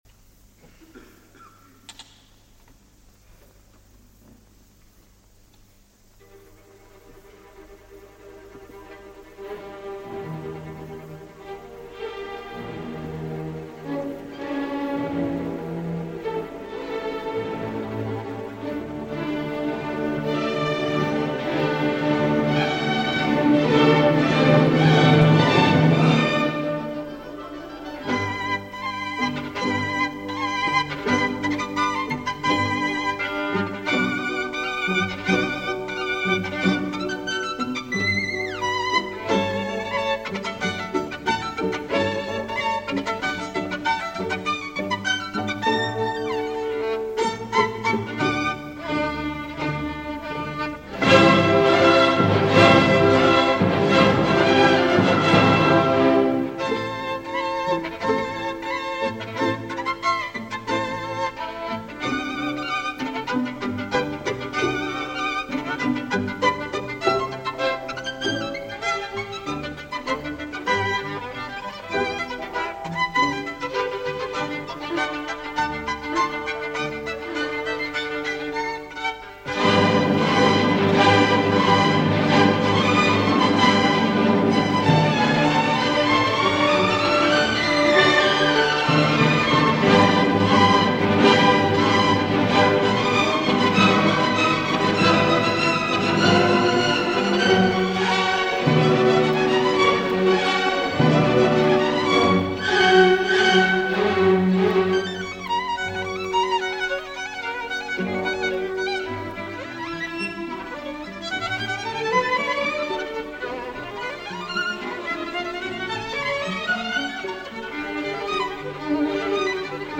Bruch – Violin Concerto no. 1 Concertgebouw Orchestra Amsterdam Zondag, 27 oktober, 1940 ; Live recording Soloists: Guila Bustabo, violin 01 - Vorspiel Allegro moderato 02 - Adagio 03 - Finale Allegro Energico